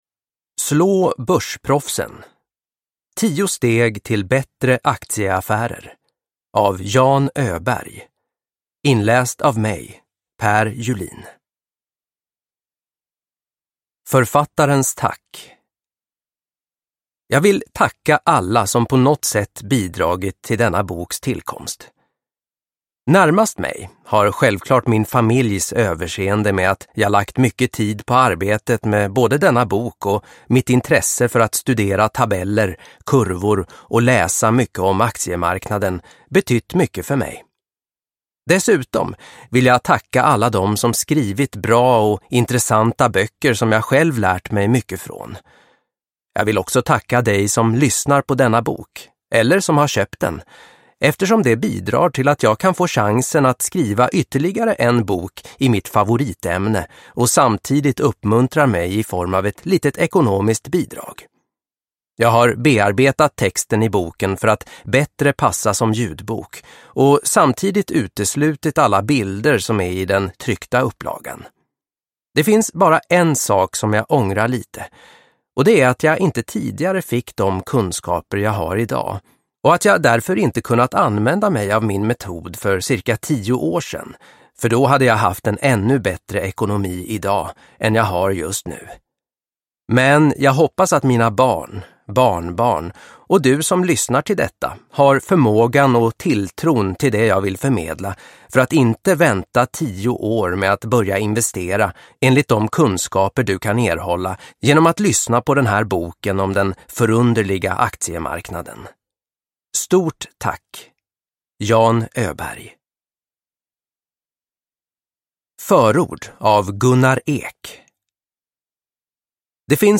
Slå börsproffsen: Tio steg till bättre aktieaffärer – Ljudbok – Laddas ner